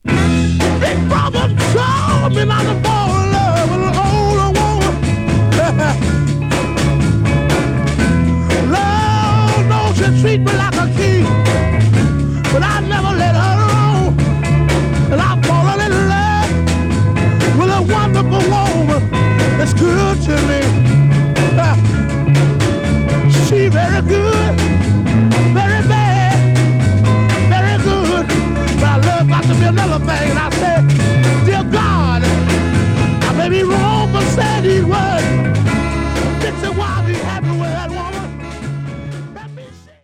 Genre: Funk/Soul, Soul